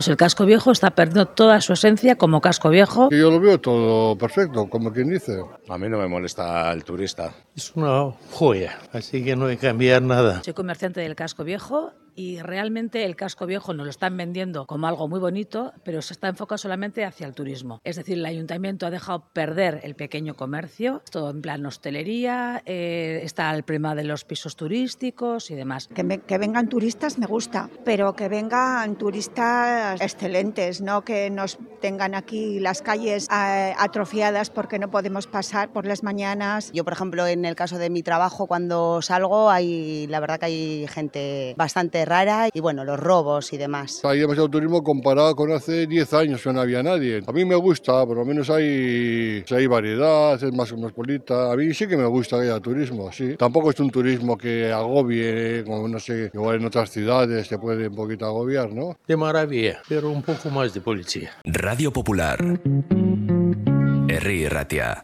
En 'Bilbao al habla' conocemos la opinión de los vecinos del Casco Viejo